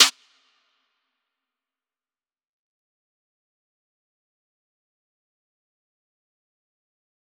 DMV3_Snare 19.wav